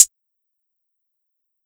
Closed Hats
pbs - real clean [ Hihat ].wav